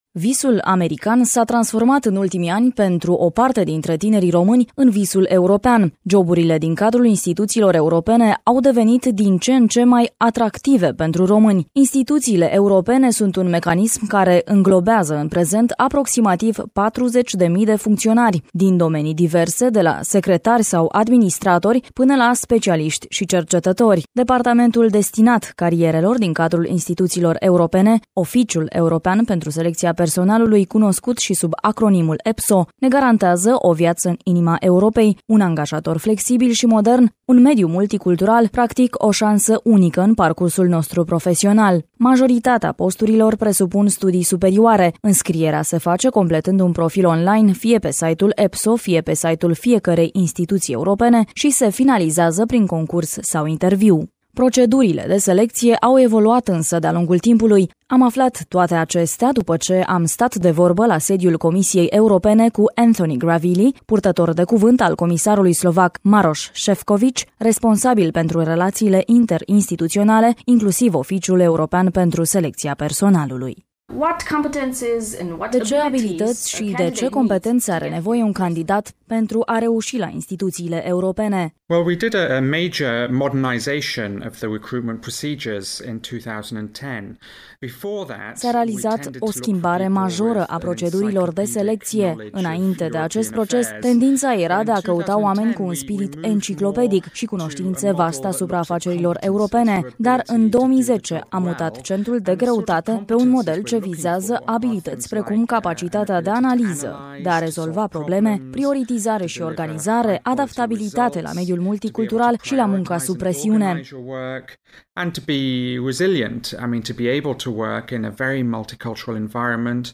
(INTERVIU) Joburile la instituțiile europene, un magnet pentru români.